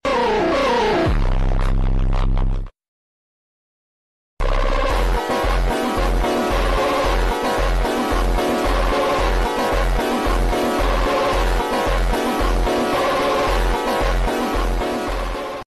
Lancer Ralliart, tuneado en casa. sound effects free download
Dagger, como lo ha llamado su dueño, es un Lancer Ralliart 2011 stock, el cual tiene un motor 4B11T (2.0 Turbo), y una transmisión SST (Sport Shift Transmission), con tracción AWD (All Wheel Drive).